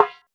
PERC.92.NEPT.wav